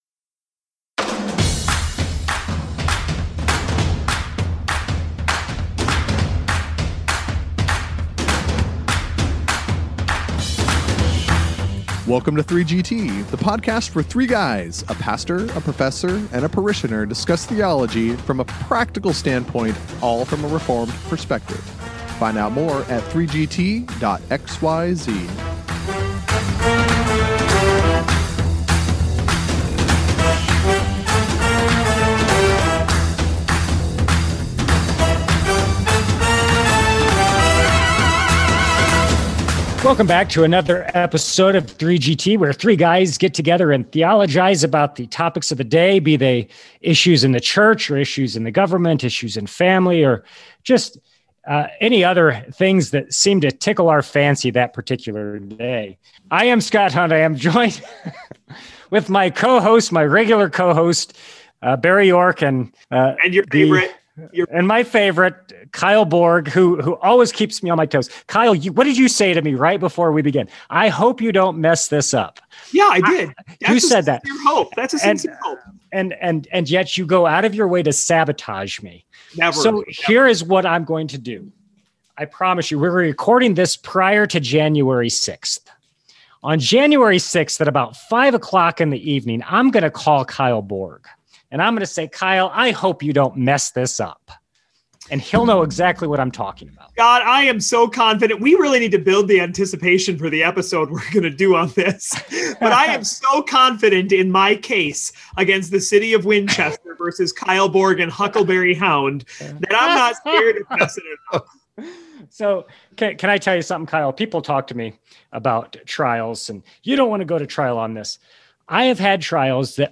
Join along then with this vigorous exchange on this episode of 3GT!